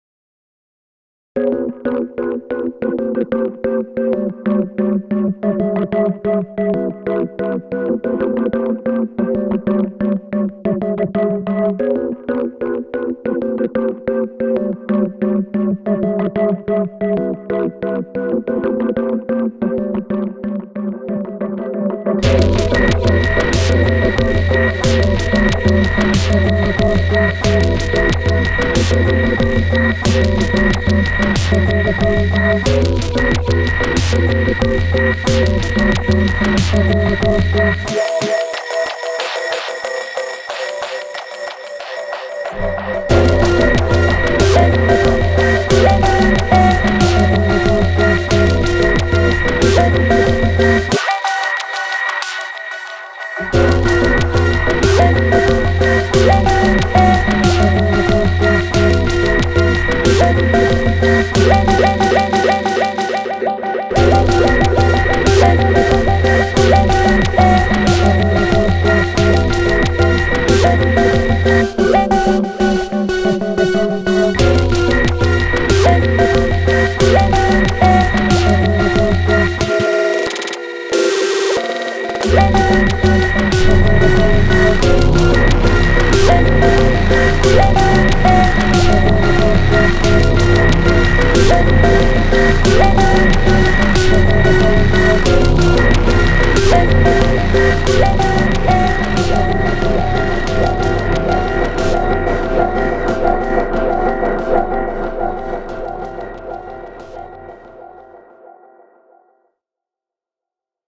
Four stems on the Octatrack, one of which was itself made on the Octatrack in the first place.